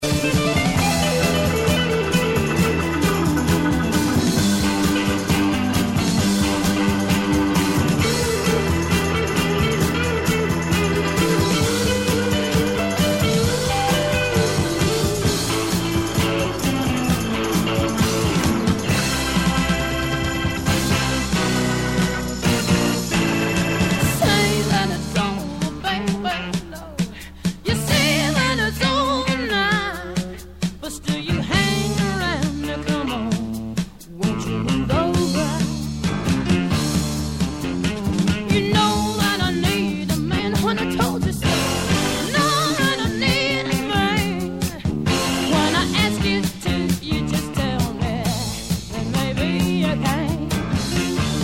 My Favorite Songs with Notable Bass Guitar Performances